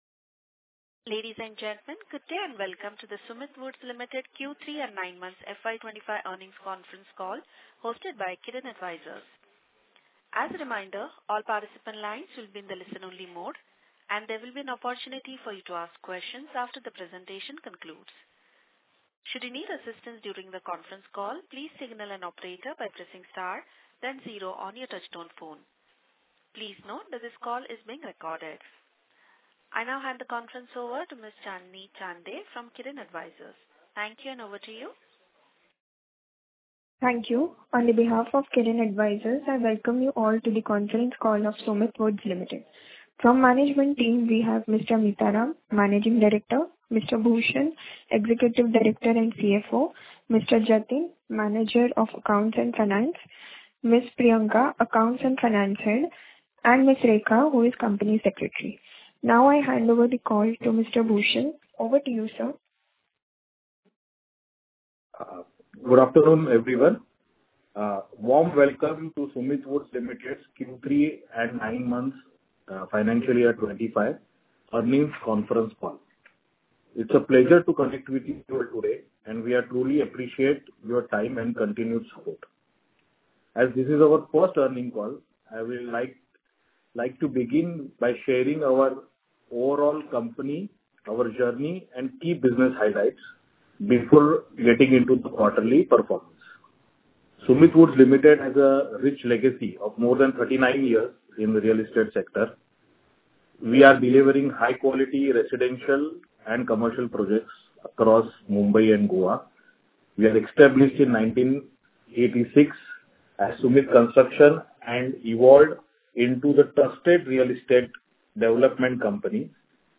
Sumit Woods Limited- Q3 & 9M Earning Conference call Audio